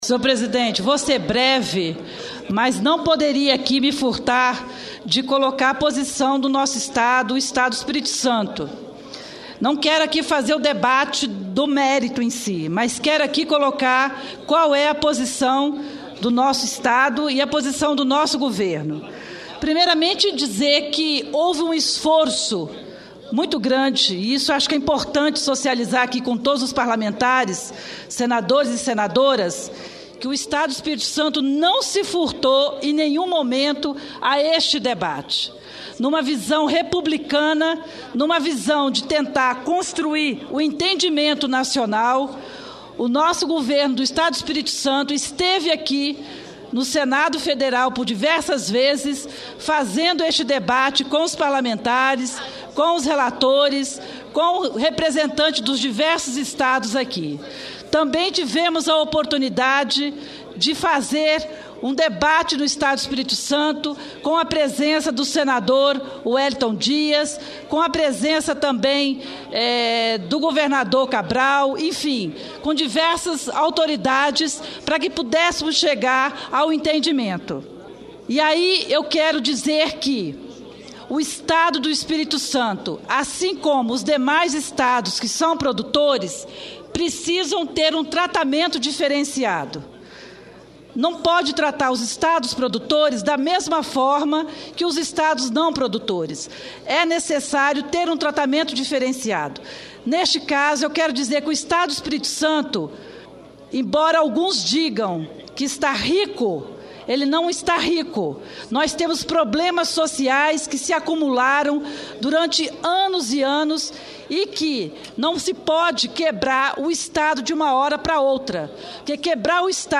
Pronunciamento da senadora Ana Rita